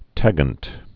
(tăgənt)